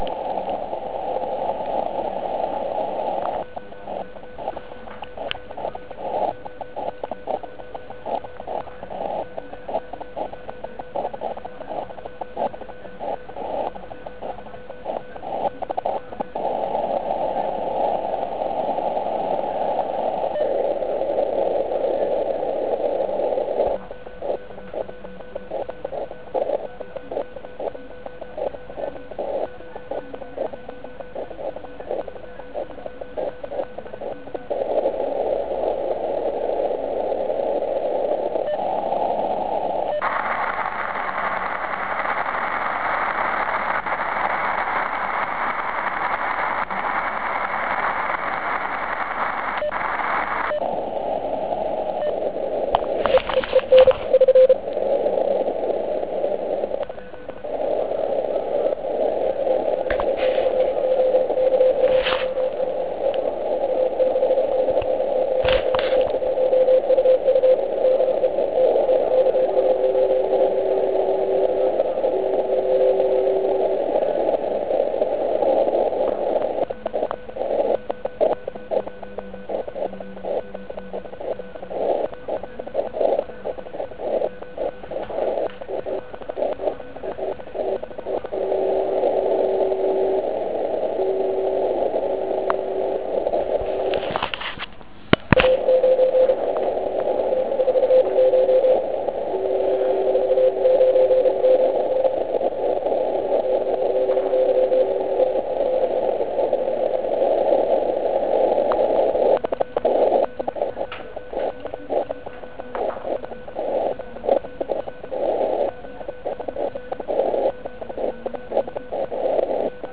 Vzhledem k nízkým kritickým kmitočtům je signál na krátké vzdálenosti v přeslechu.